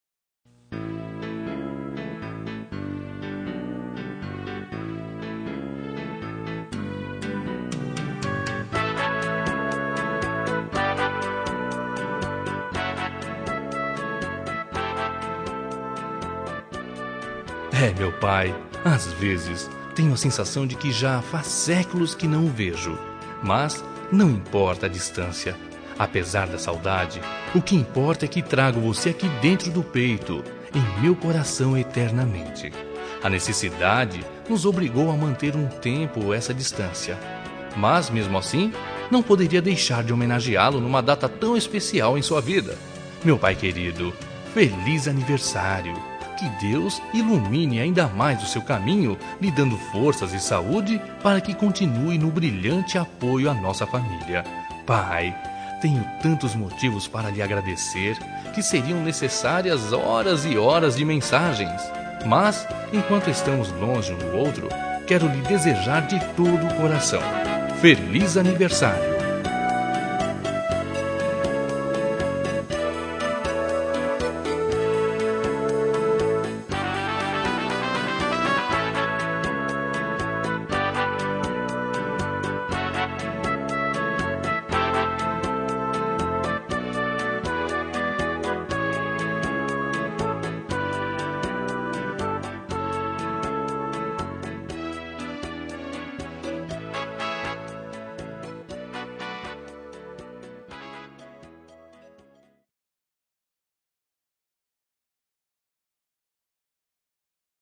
Telemensagem de Aniversário de Pai – Voz Masculina – Cód: 1512 Distante